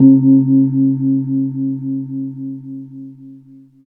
Index of /90_sSampleCDs/Roland L-CD701/PRC_Asian 2/PRC_Gongs